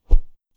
Close Combat Swing Sound 6.wav